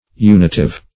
Search Result for " unitive" : The Collaborative International Dictionary of English v.0.48: Unitive \U"ni*tive\, a. [LL. unitivus: cf. F. unitif.]